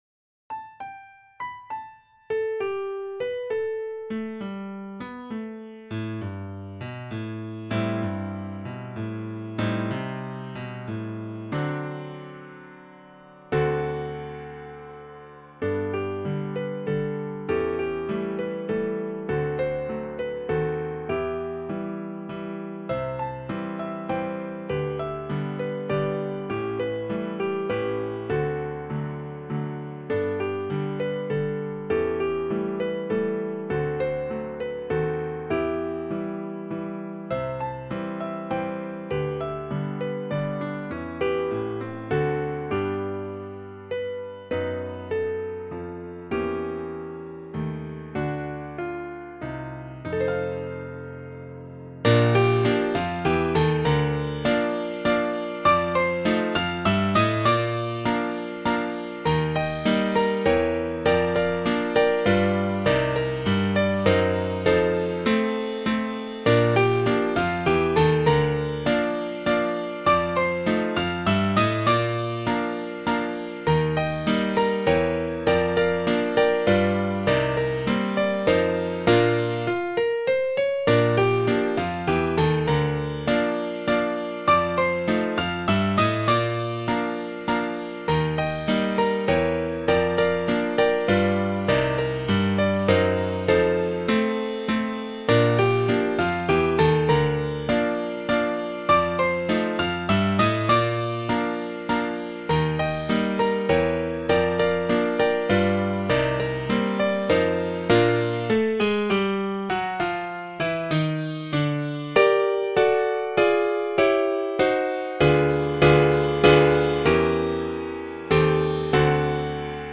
Ragtime Sheet Music
and one of the best-known Ragtime Waltzes.
for piano